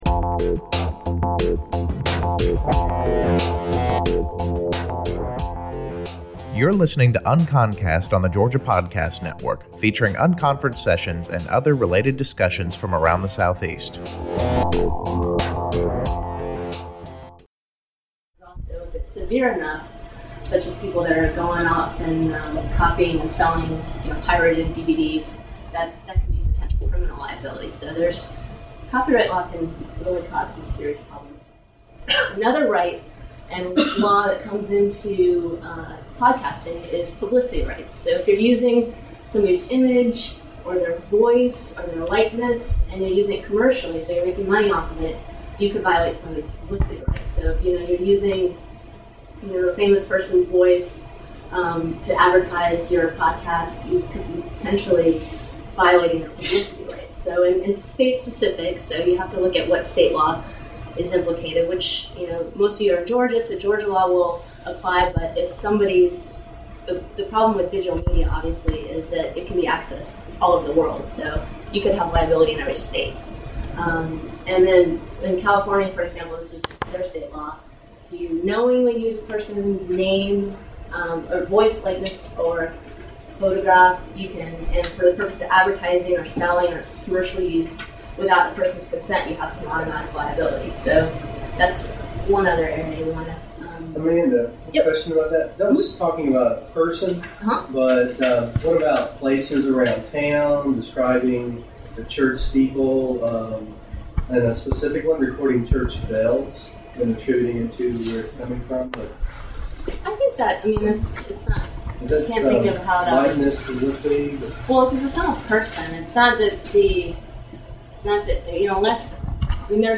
PodCamp Atlanta took place March 17 and 18 at Emory University.
NOTE : This is an incomplete recording that picks up after the session had started and drops off before it's over.